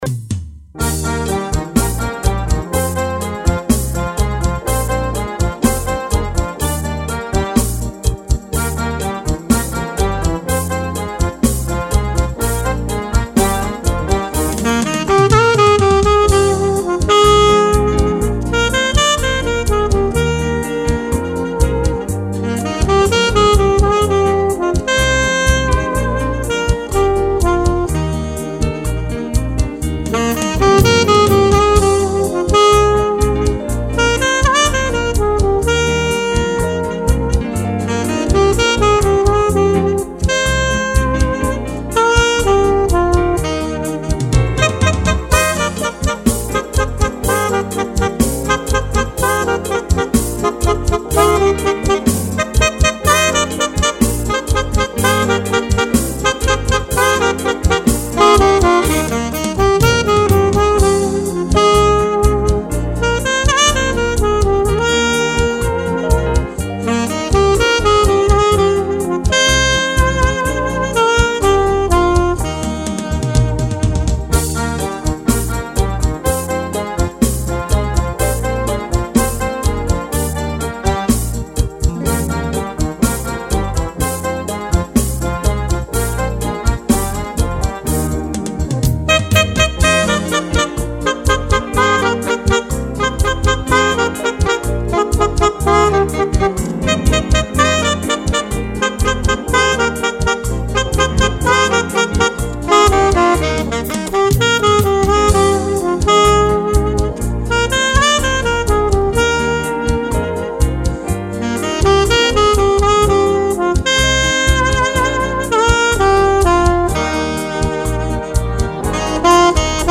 839   03:20:00   Faixa:     Bolero